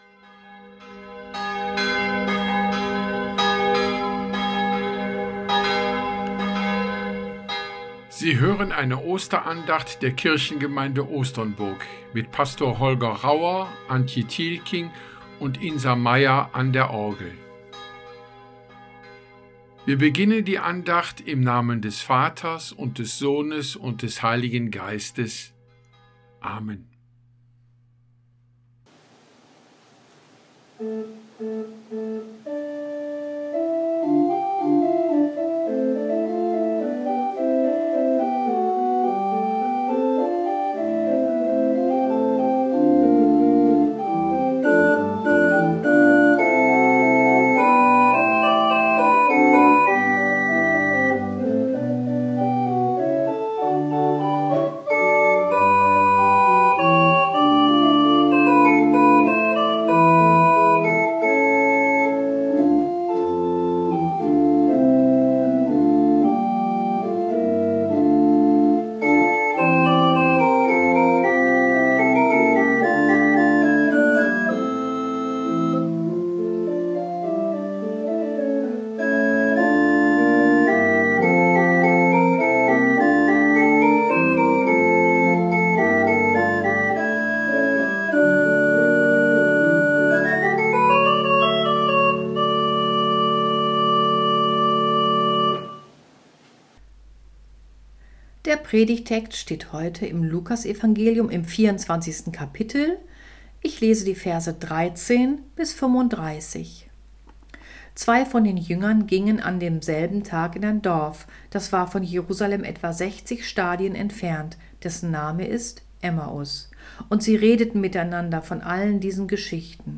Andacht zum Ostermontag
Lied EG 107 (1,2,3): Wir danken dir, Herr Jesu Christ Lied EG 251 (1,2): Herz und Herz vereint zusammen